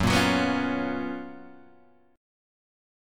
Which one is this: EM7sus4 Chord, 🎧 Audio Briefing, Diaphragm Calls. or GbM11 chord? GbM11 chord